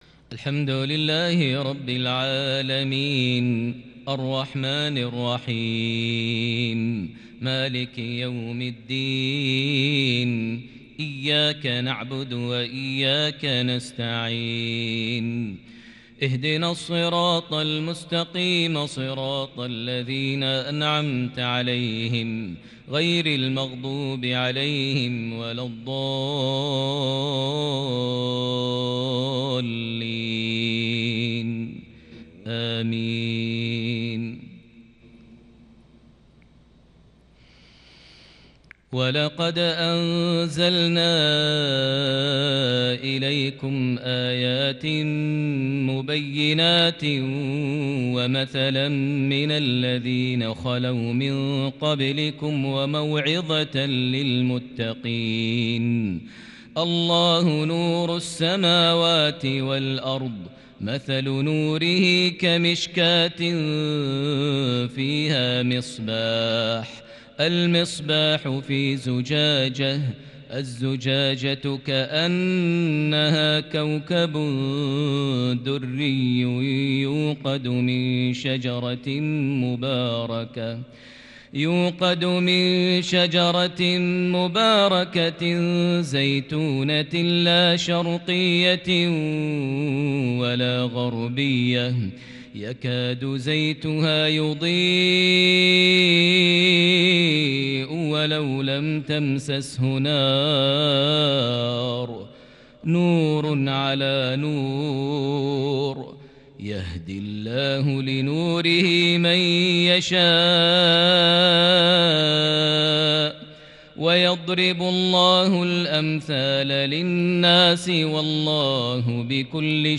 عشائية متألقة بتفرد وتحبيرالكرد من سورة النور (34 - 46) | الأربعاء 6 صفر 1442هـ > 1442 هـ > الفروض - تلاوات ماهر المعيقلي